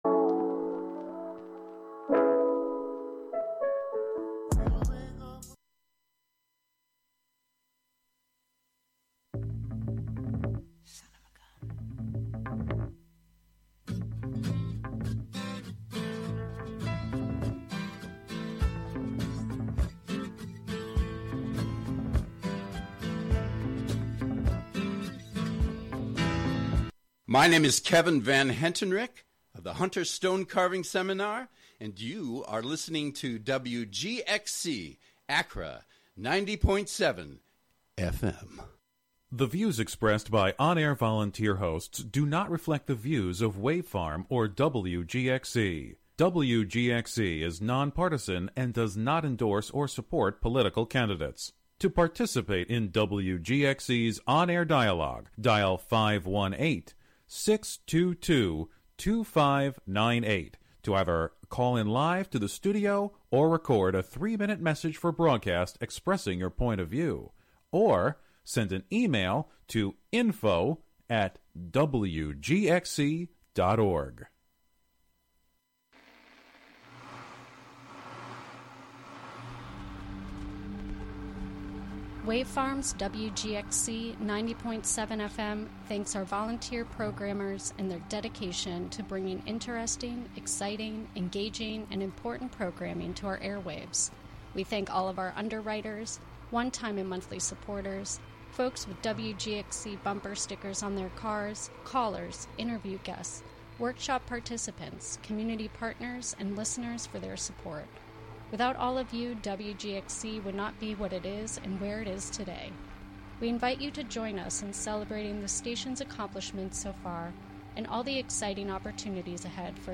8pm American Tarragon brings you the blissed out sound... American Tarragon brings you the blissed out sounds of folk, ambient, rock-n-roll, psychedelia, electronica, new age, world music and beyond.